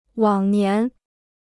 往年 (wǎng nián) Free Chinese Dictionary